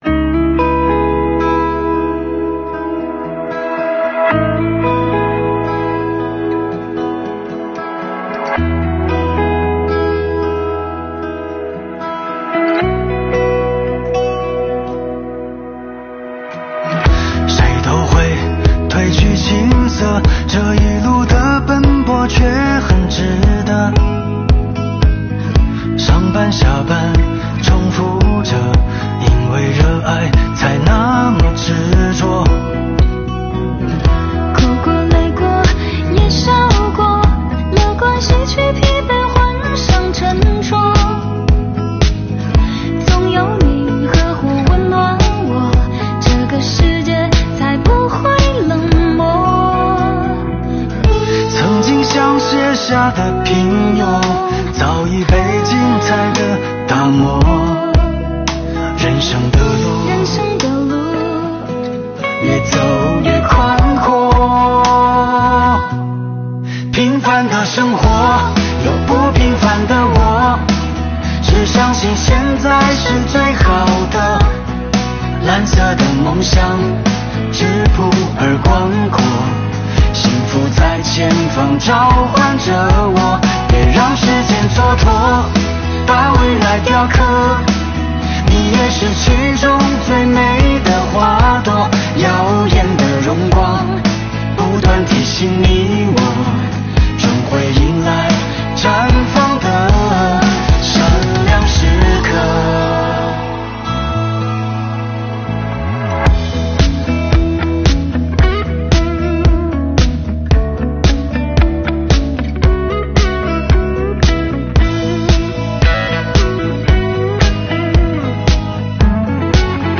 2022年新年伊始，我们用税务人录制的歌曲，为大家制作了一本音乐挂历，并将全年办税时间做了标注，伴着歌声开启新征程吧。